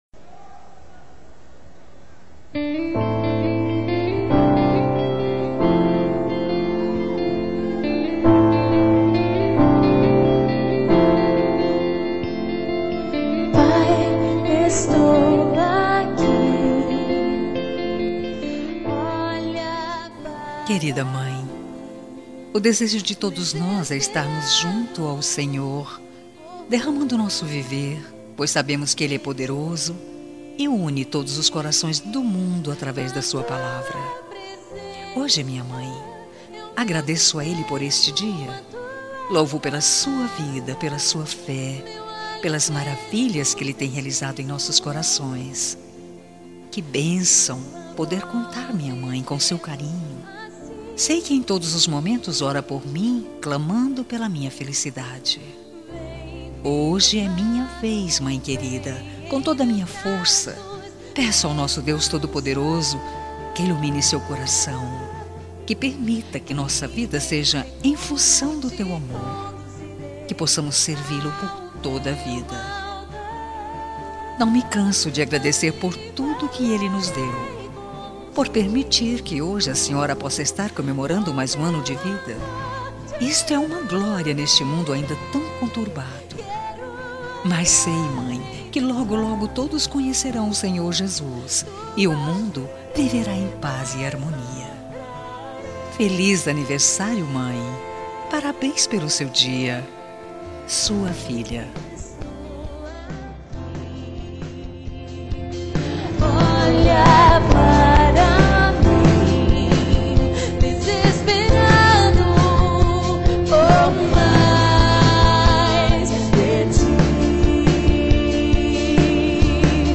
Telemensagem Evangélica Anversário Mãe | Com Reação e Recado Grátis